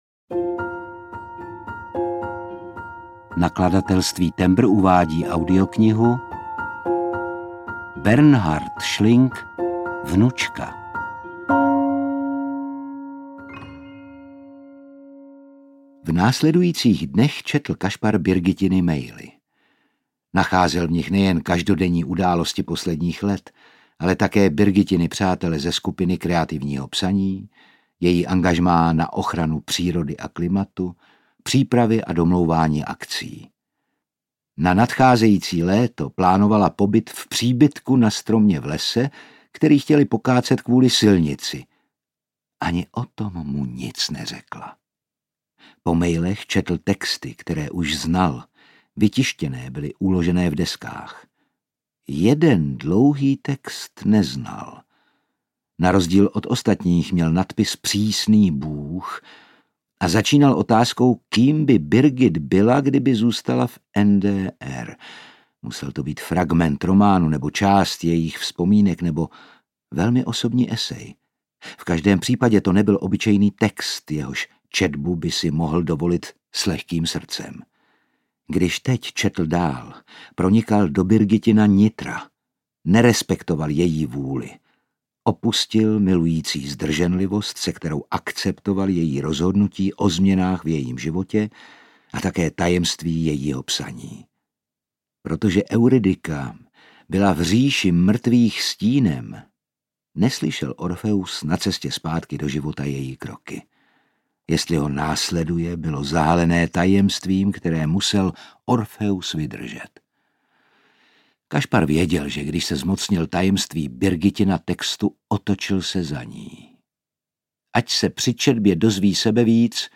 Vnučka audiokniha
Ukázka z knihy